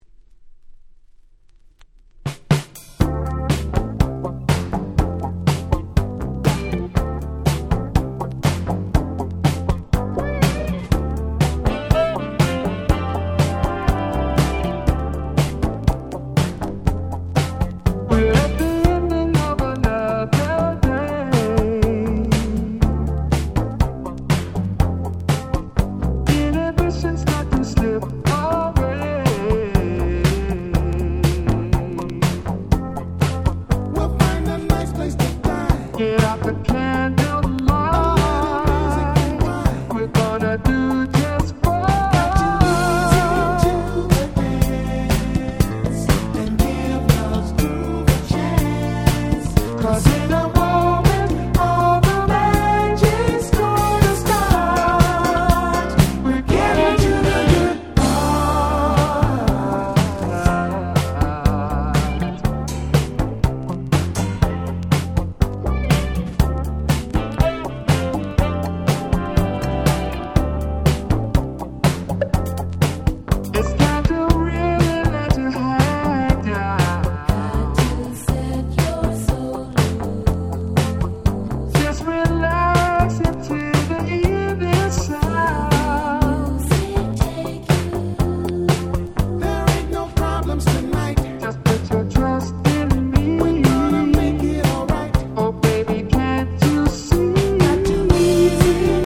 82' Very Nice Disco / Boogie !!
ディスコブギー